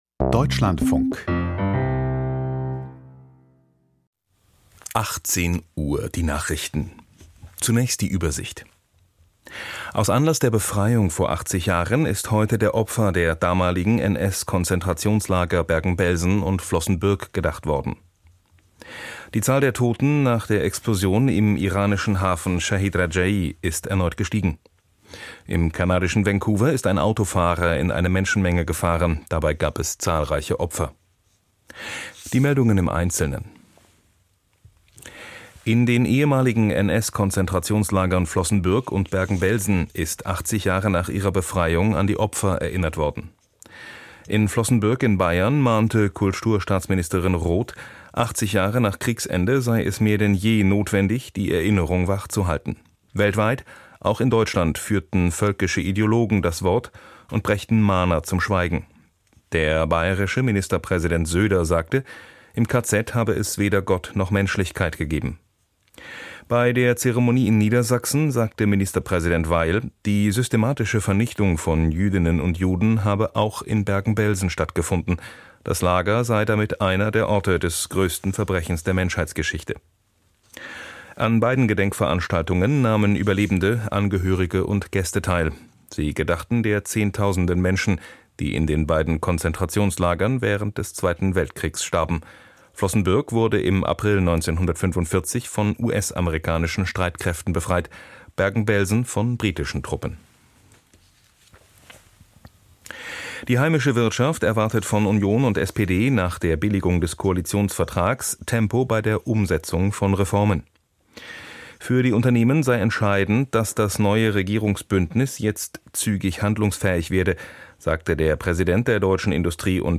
Die Deutschlandfunk-Nachrichten vom 27.04.2025, 18:00 Uhr